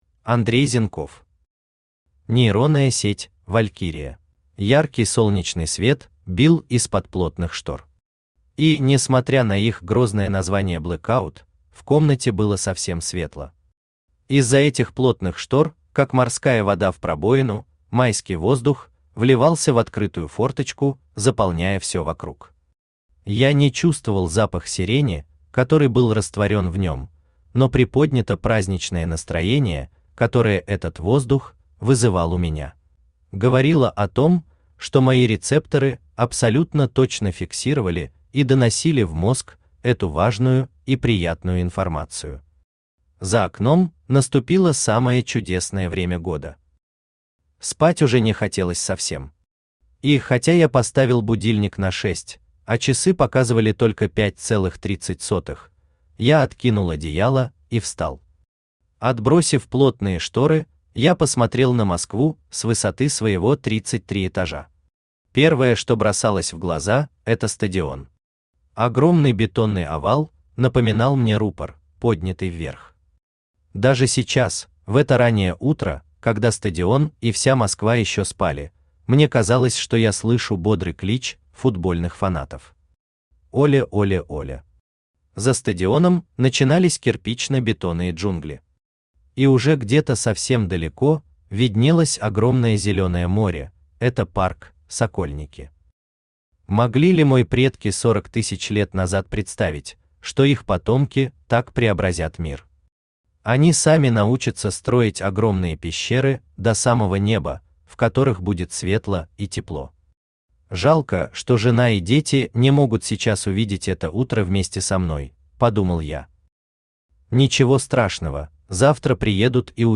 Aудиокнига Нейронная сеть «Валькирия» Автор Андрей Зенков Читает аудиокнигу Авточтец ЛитРес.